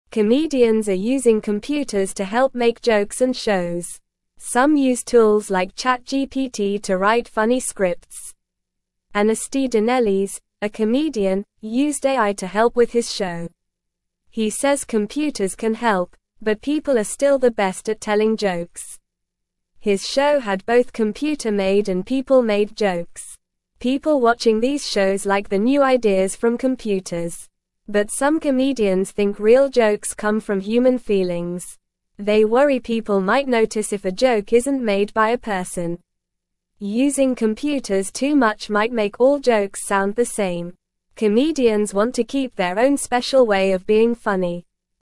Normal
English-Newsroom-Beginner-NORMAL-Reading-Comedians-Use-Computers-to-Make-Jokes-and-Shows.mp3